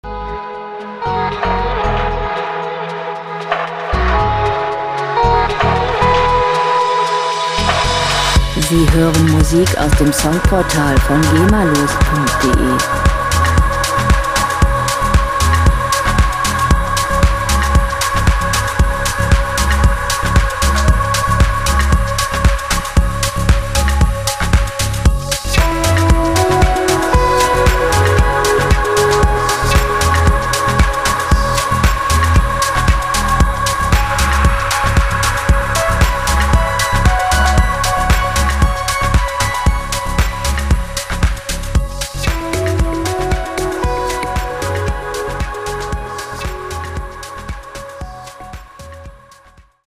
Musikstil: Dream House
Tempo: 115 bpm
Tonart: Cis-Dur
Charakter: lebendig, erotisierend
Instrumentierung: Synthesizer, E-Gitarre, Vocals